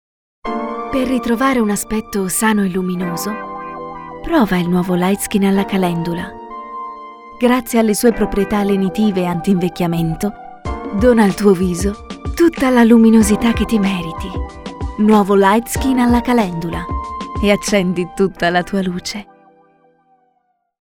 Speaker italiana, voce giovane, duttile, sensuale, caratterizzabile, adatta per documentari, spot, radiocomunicati, audiolibri, letture interpretate, videogames.
Sprechprobe: Werbung (Muttersprache):